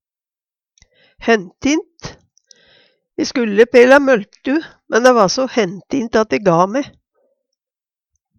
DIALEKTORD PÅ NORMERT NORSK hentint spreidd, ikkje tett Eksempel på bruk E skulde pela møltu, men dæ va so hentint at e ga me.